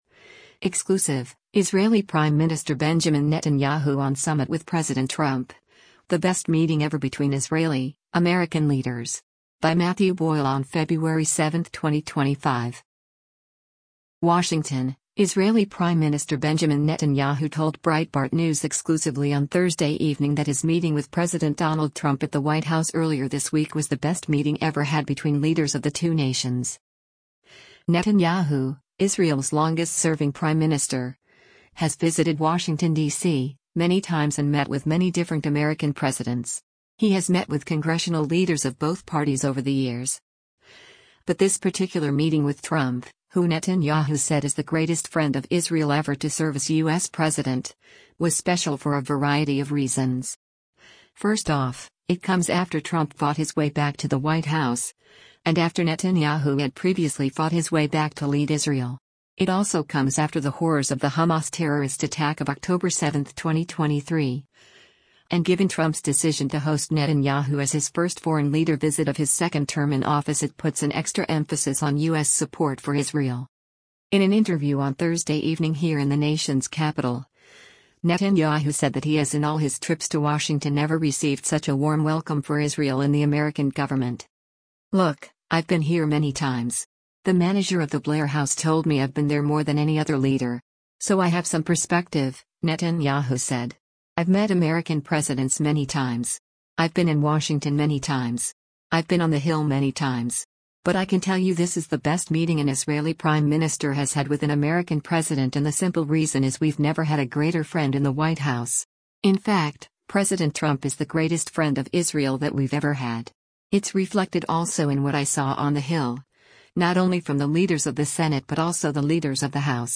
In an interview on Thursday evening here in the nation’s capital, Netanyahu said that he has in all his trips to Washington never received such a warm welcome for Israel in the American government.